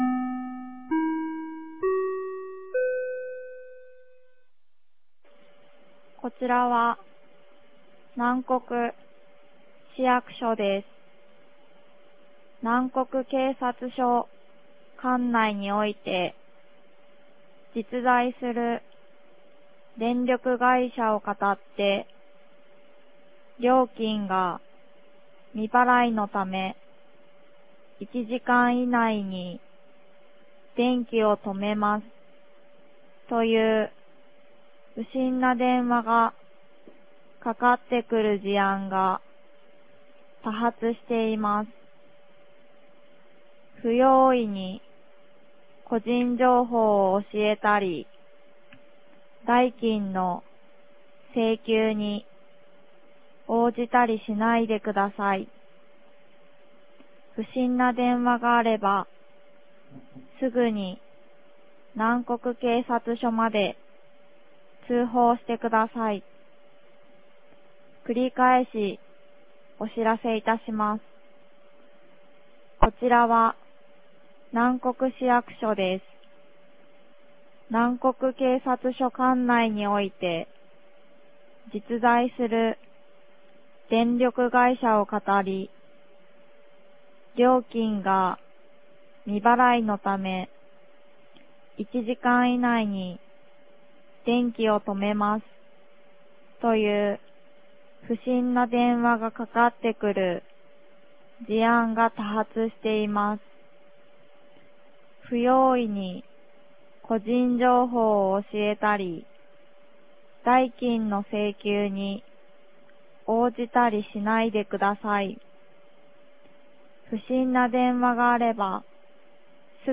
2023年06月27日 13時17分に、南国市より放送がありました。
放送音声